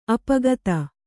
♪ apagata